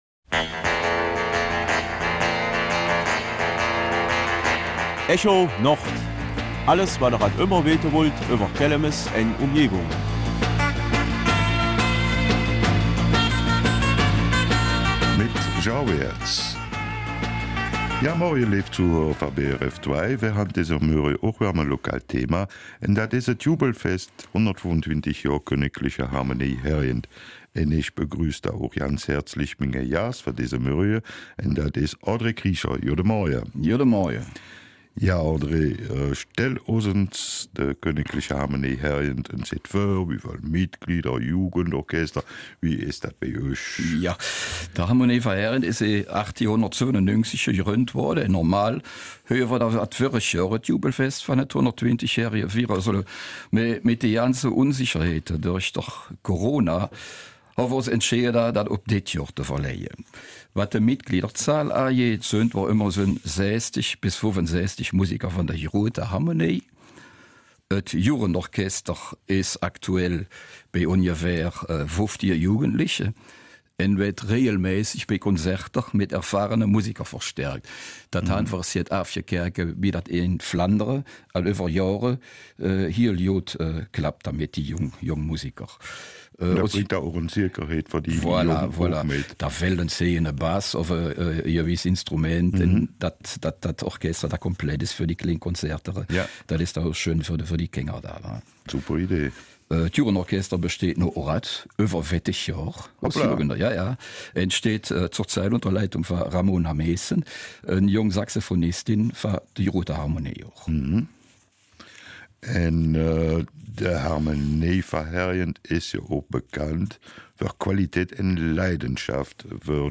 Kelmiser Mundart: Jubelfest 125 Jahre Kgl.